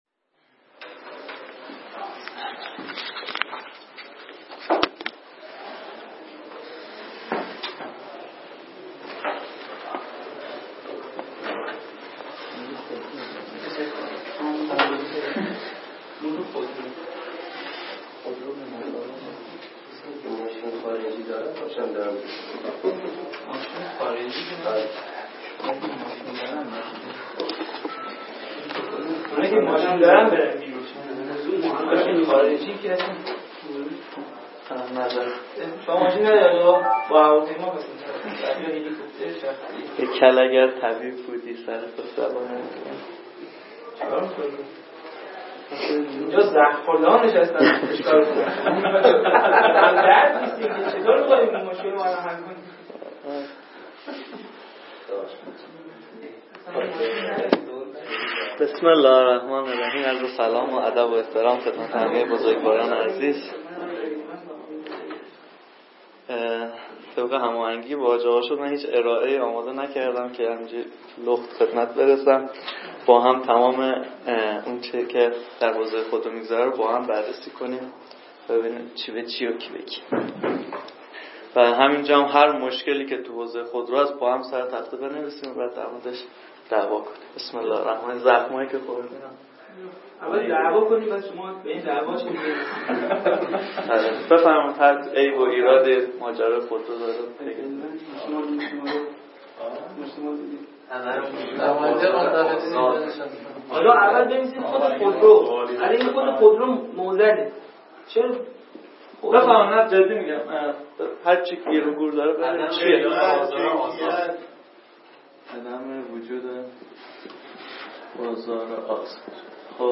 نشست علمی
❇همراه با نقد و پرسش و پاسخ چالشی